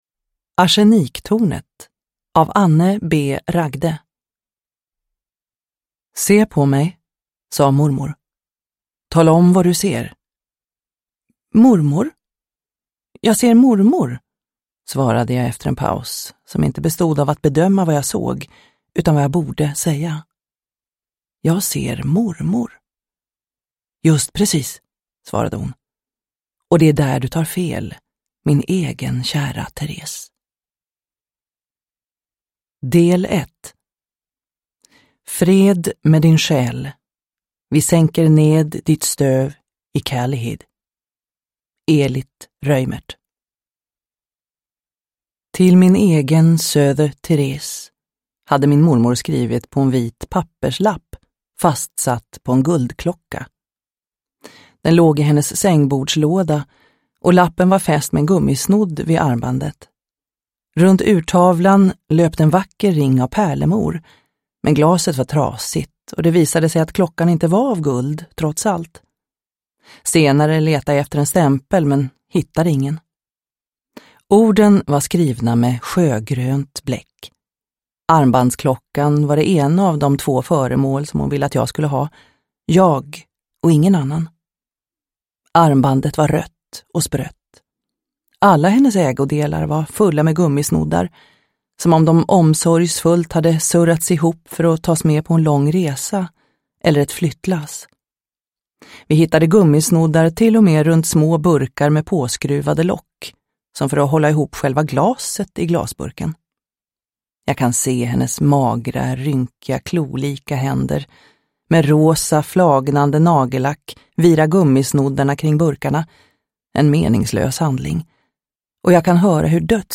Arseniktornet – Ljudbok – Laddas ner